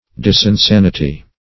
Search Result for " disensanity" : The Collaborative International Dictionary of English v.0.48: Disensanity \Dis`en*san"i*ty\, n. [Pref. dis- + en (L. in) + sanity.] Insanity; folly.